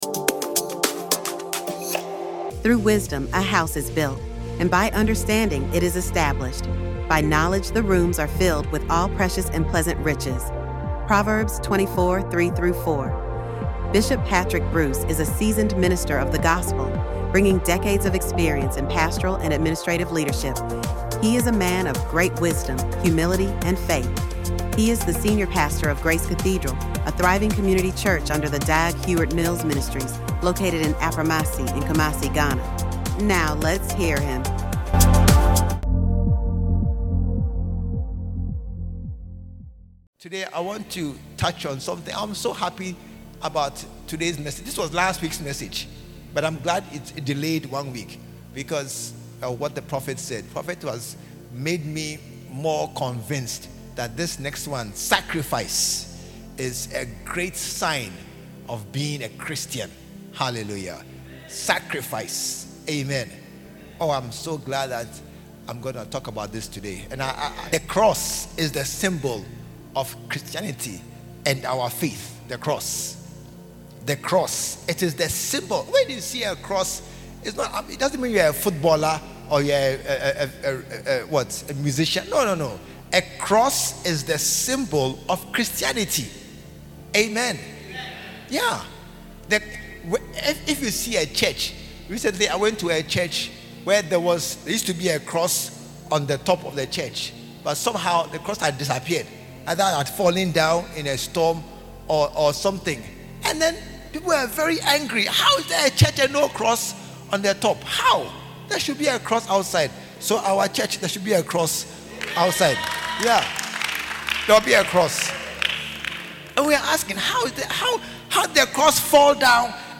In this soul-stirring message, the preacher dives deep into the heart of Christian identity, revealing that sacrifice is not just a concept—it’s a calling.